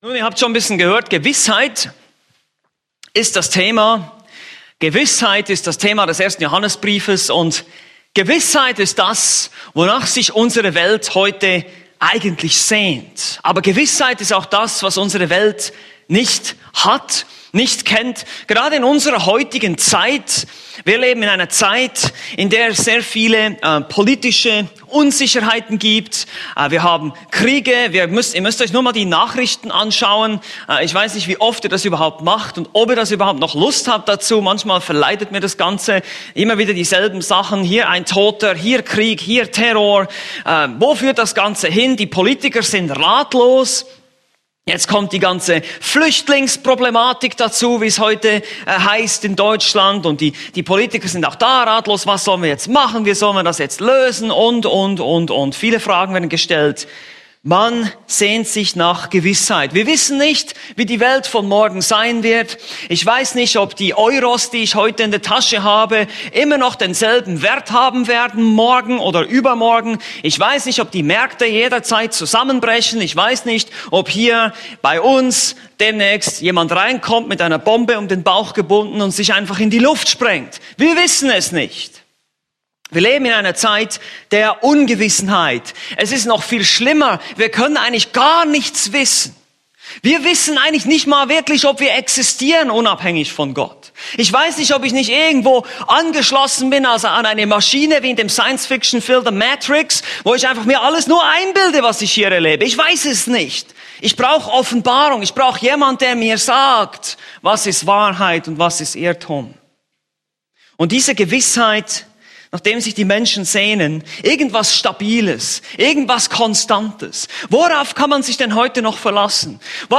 Predigt: "1.